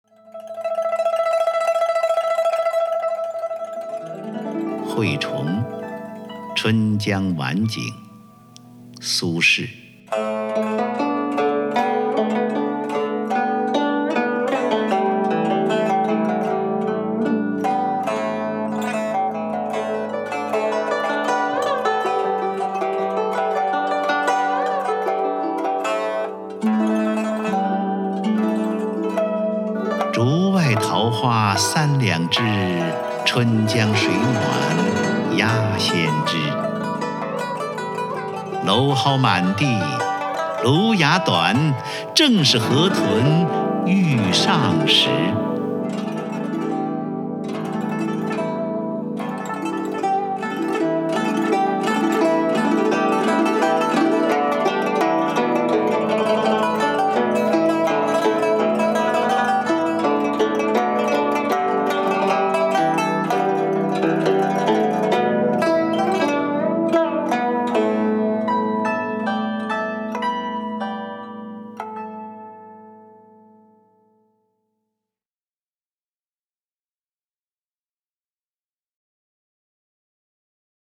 首页 视听 名家朗诵欣赏 张家声
张家声朗诵：《惠崇《春江晚景》·其一》(（北宋）苏轼)　/ （北宋）苏轼